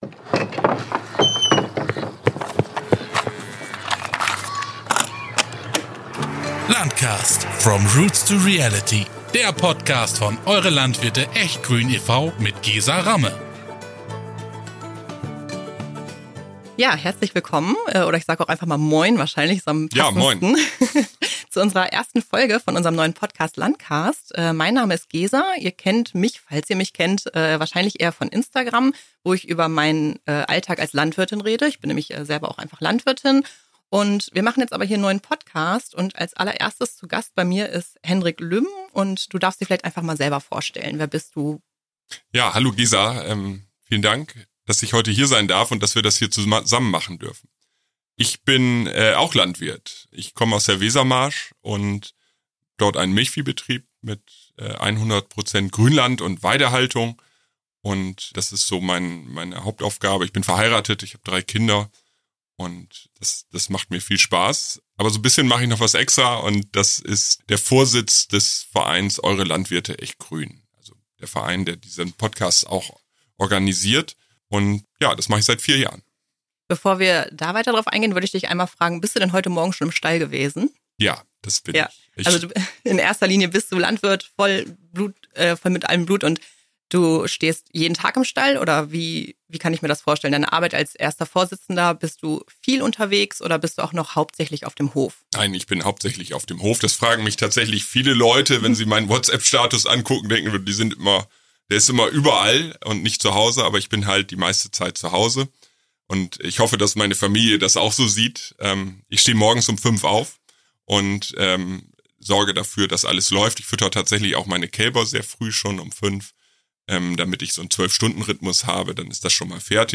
Zwischen ehrlicher Selbstkritik und trockenem Hofhumor entsteht etwas, das man nicht planen kann: ein Gespräch, das Landwirtschaft nicht erklärt, sondern spürbar macht.